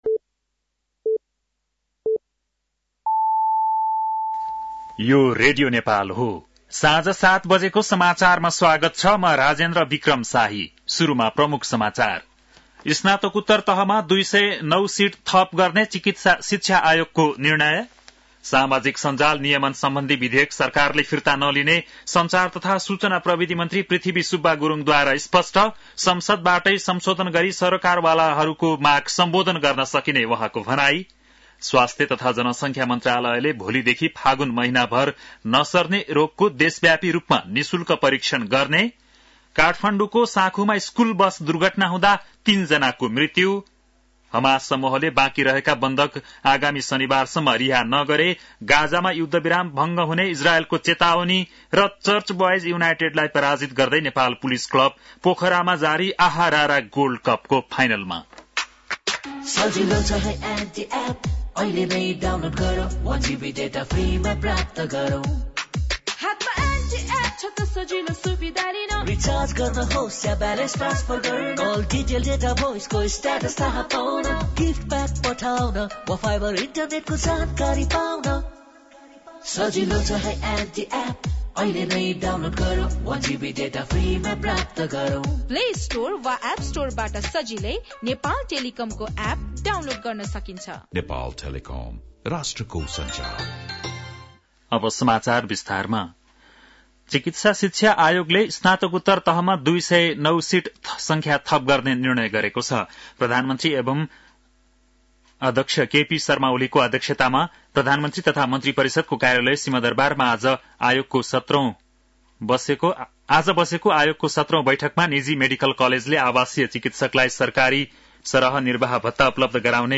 बेलुकी ७ बजेको नेपाली समाचार : १ फागुन , २०८१